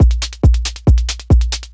Index of /90_sSampleCDs/Best Service ProSamples vol.45 - Techno ID [AIFF, EXS24, HALion, WAV] 1CD/PS-45 AIFF Techno ID/PS-45 AIF loops/AIF drum-loops/AIF main-version